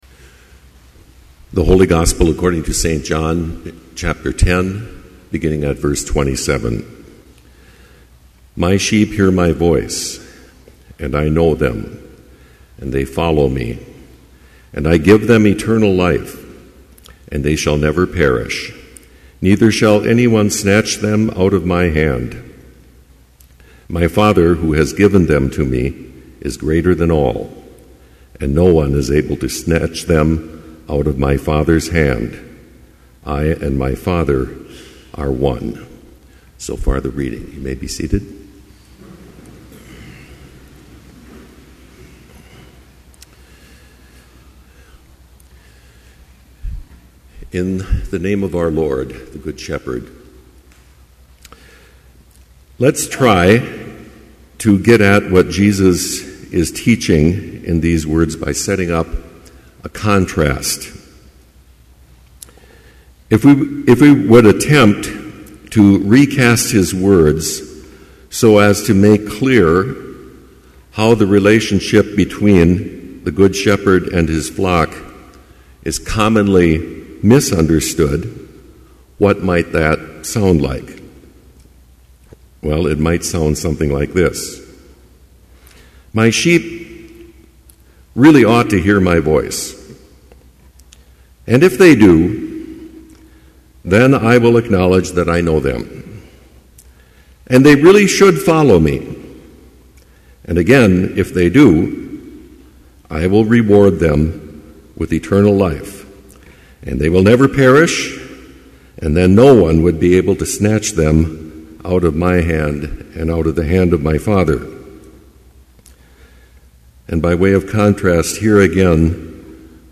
Complete Service
• Prelude
• Homily
This Chapel Service was held in Trinity Chapel at Bethany Lutheran College on Tuesday, May 10, 2011, at 10 a.m. Page and hymn numbers are from the Evangelical Lutheran Hymnary.